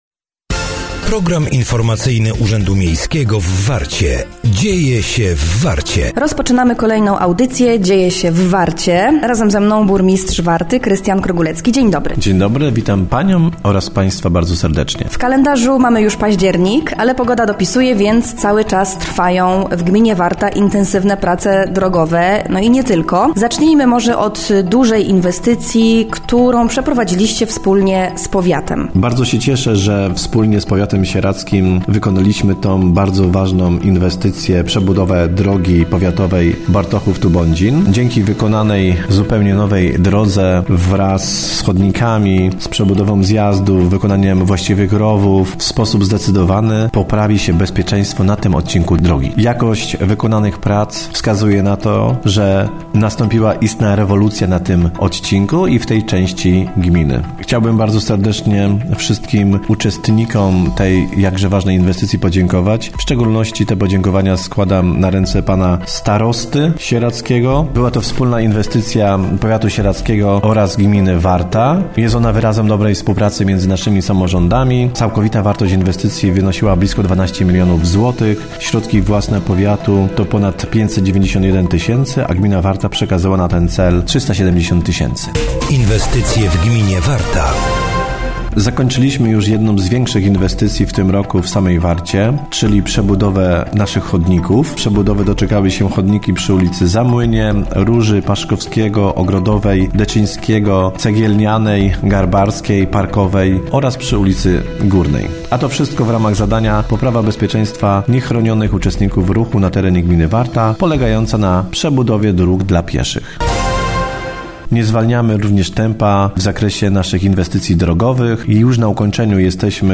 Dziś na antenie Naszego Radia i Naszego Radia Nostalgicznie można było posłuchać kolejnej audycji Dzieje się w Warcie.